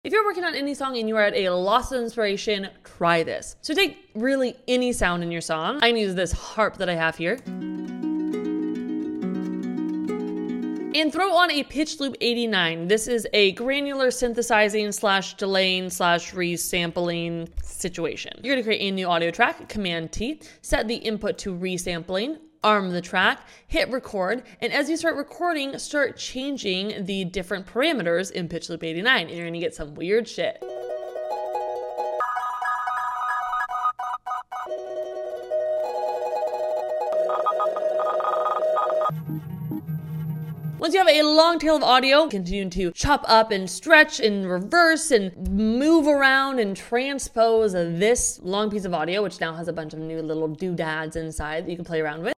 Pitchloop89 comes with Max, so you’ll only have this effect if you have Ableton Suite (or you buy the max upgrade).